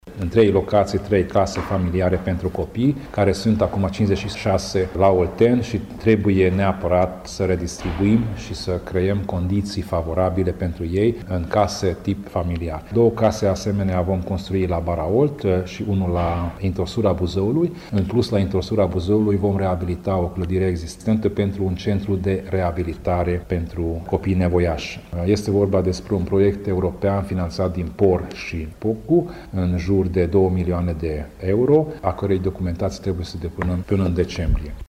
Preşedintele Consiliului Judeţean Covasna, Tamas Sandor: